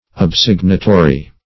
Search Result for " obsignatory" : The Collaborative International Dictionary of English v.0.48: Obsignatory \Ob*sig"na*to*ry\, a. Ratifying; confirming by sealing.